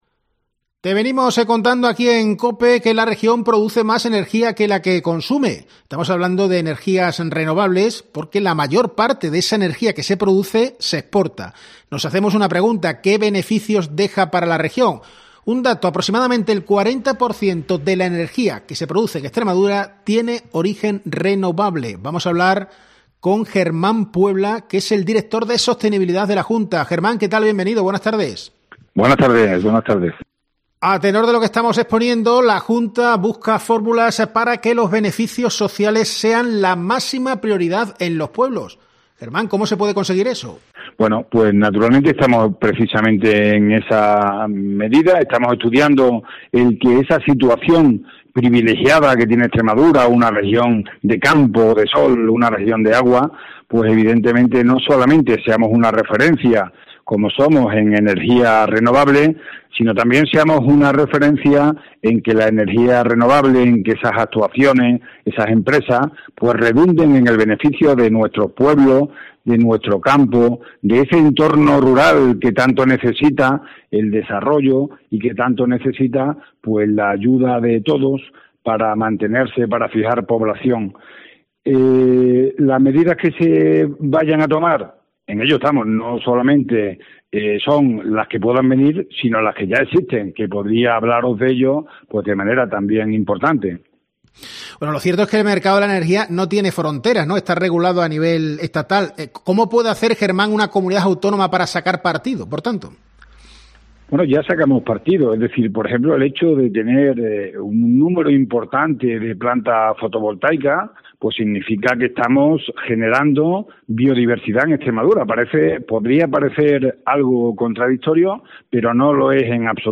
Al hilo de esto, la Junta confirma a COPE que estudian alternativas para que las empresas que se enriquezcan gracias a Extremadura también dejen rédito a la región. En COPE hemos entrevistado a Germán Puebla, director de Sostenibilidad.